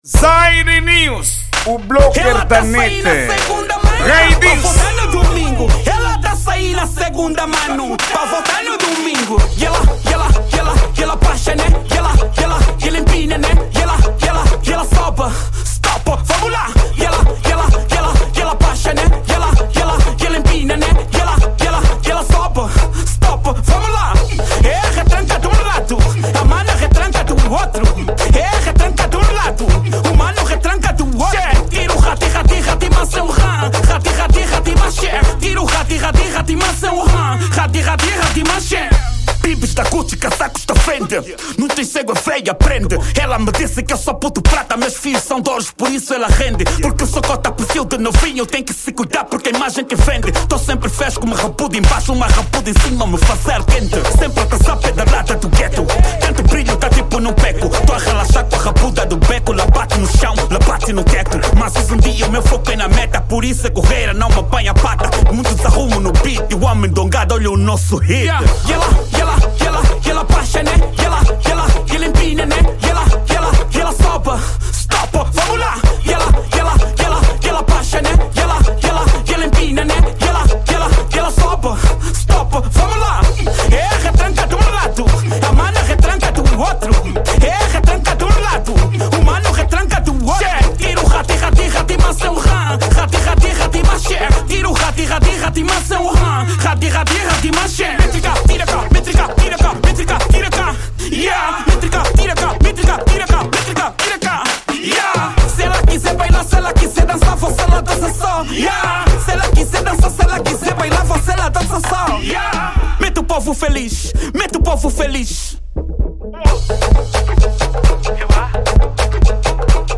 Gênero:Afro House